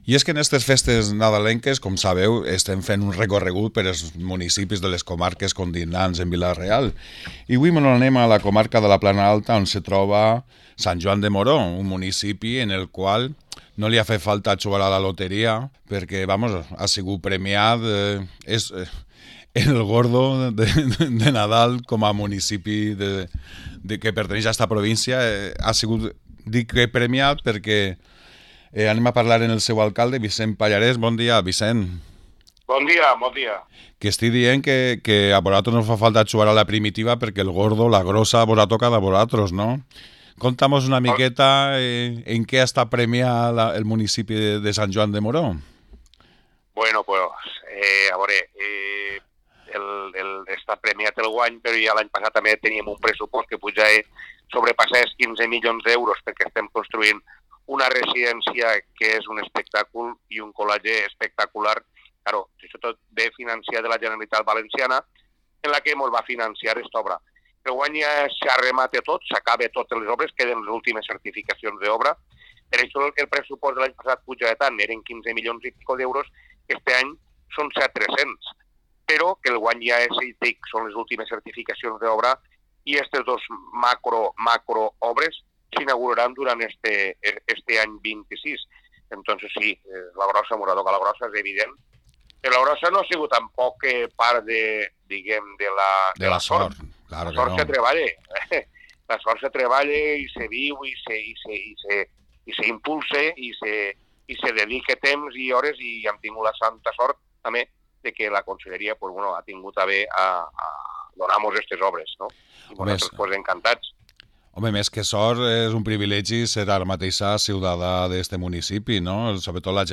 Parlem amb l´alcalde de Sant Joan de Moró, Vicent Pallares